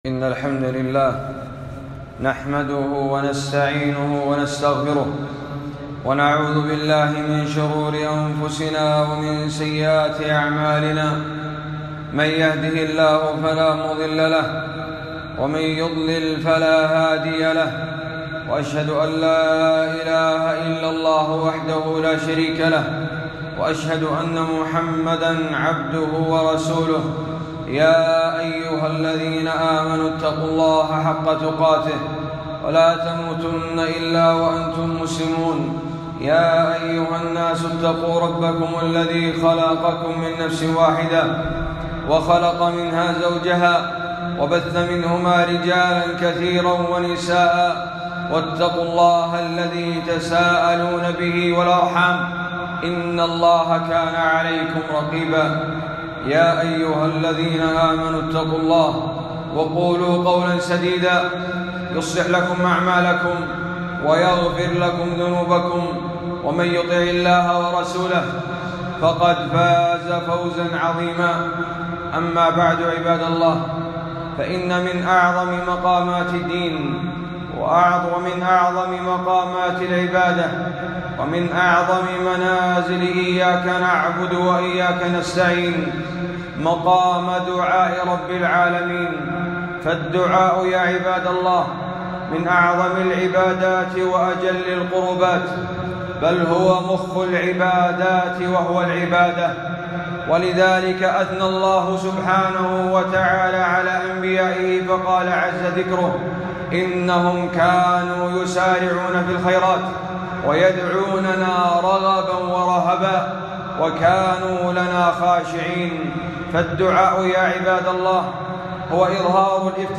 خطبة - موطنان من مواطن إجابة الدعاء